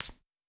Court Squeak Normal.wav